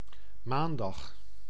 Ääntäminen
Ääntäminen France (Ouest): IPA: [lɛ̃.di] France (Avignon): IPA: [lœ̃ⁿ.d͡zi] Tuntematon aksentti: IPA: /lœ̃.di/ Haettu sana löytyi näillä lähdekielillä: ranska Käännös Ääninäyte Substantiivit 1. maandag {m} Muut/tuntemattomat 2.